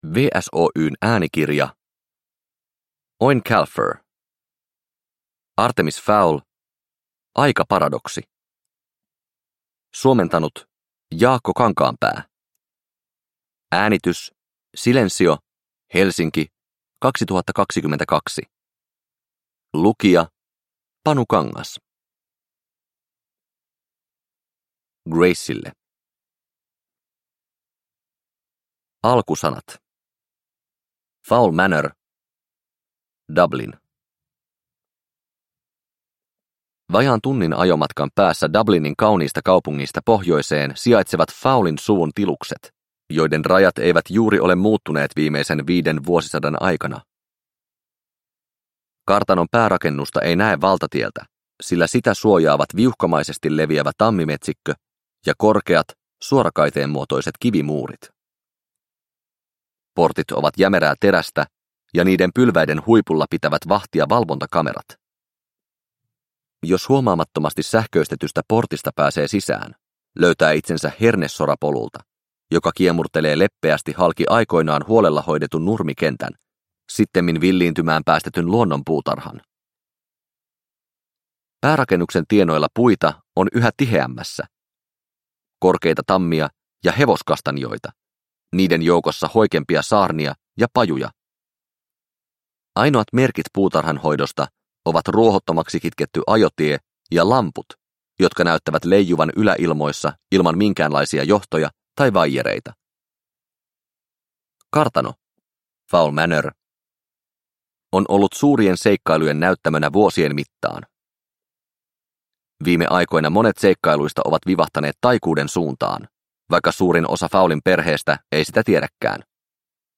Artemis Fowl: Aikaparadoksi – Ljudbok – Laddas ner